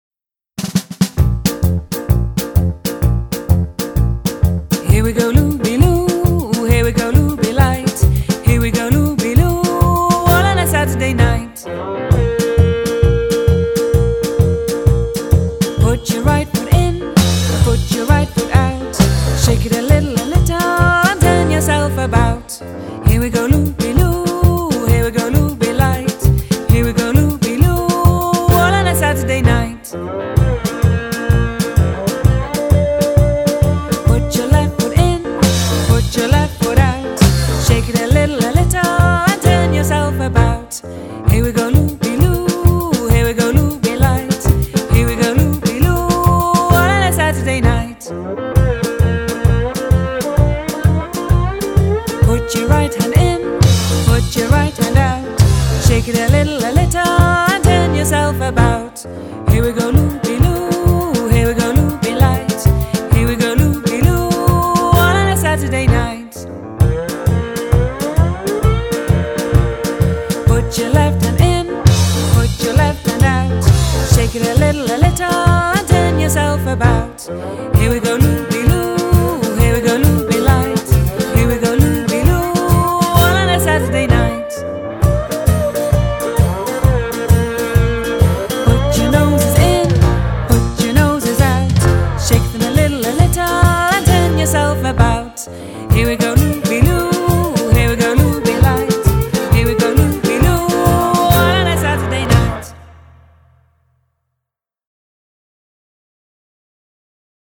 Fun & Punk